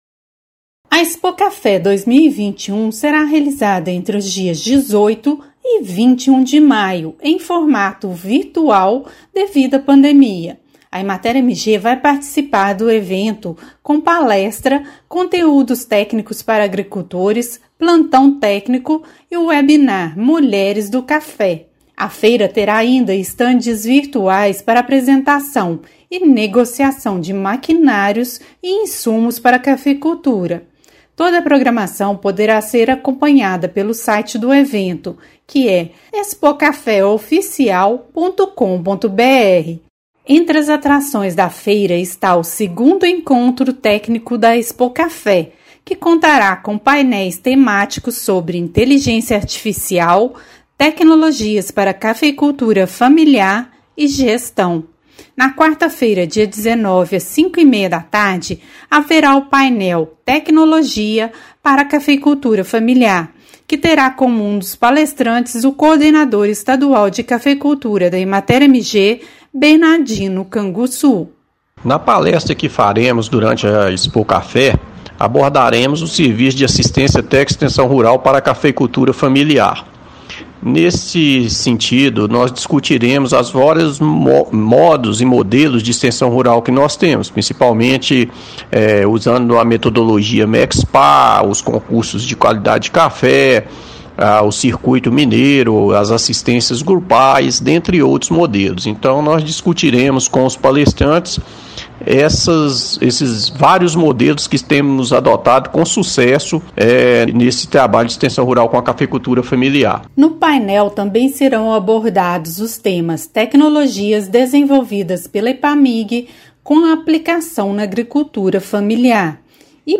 A Empresa de Assistência Técnica e Extensão Rural do Estado de Minas Gerais (Emater-MG) tem programação no evento. Ouça matéria de rádio.
MATÉRIA_RÁDIO_EMATER_EXPOCAFÉ.mp3